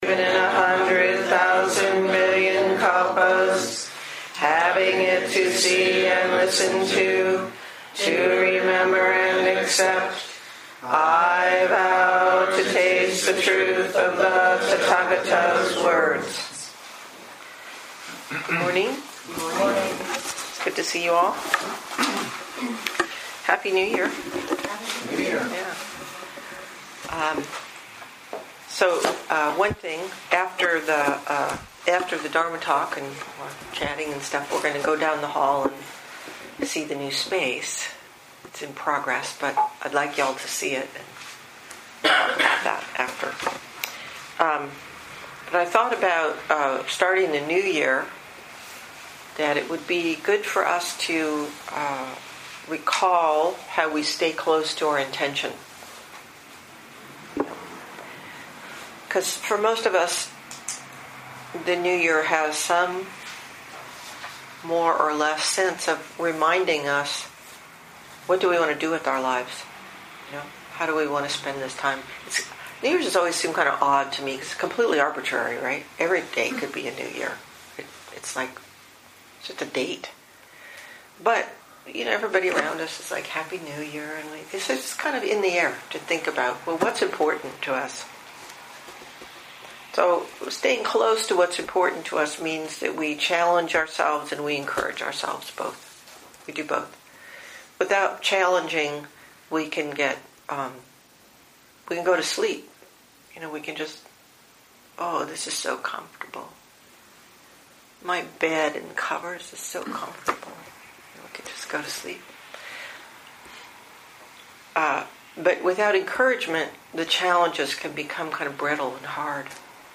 2017 in Dharma Talks